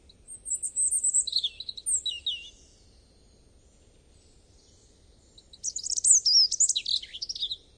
robin2.ogg